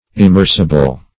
Immersable \Im*mers"a*ble\, a.
immersable.mp3